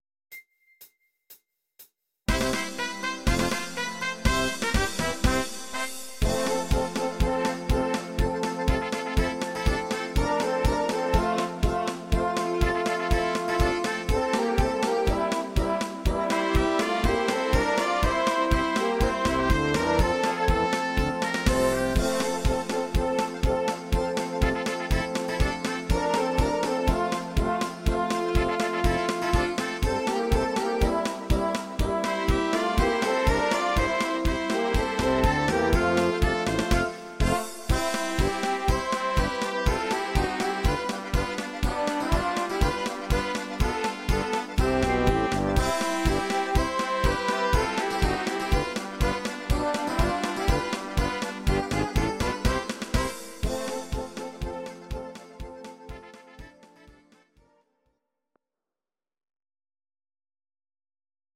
These are MP3 versions of our MIDI file catalogue.
Please note: no vocals and no karaoke included.
Your-Mix: Jazz/Big Band (731)